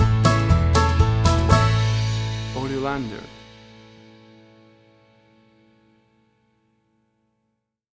Tempo (BPM): 120